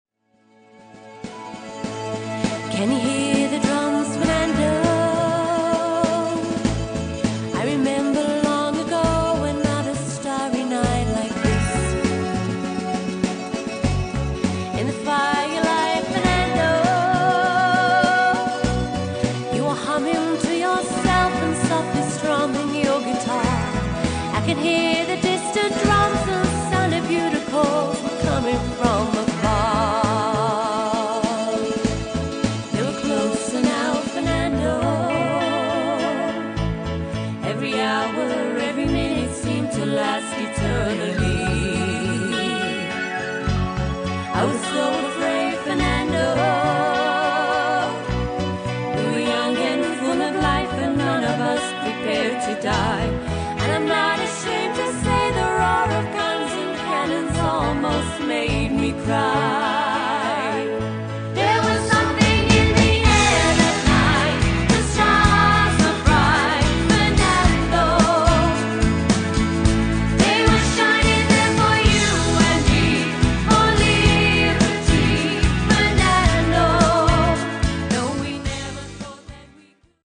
a 5 piece live band with 5 part harmonies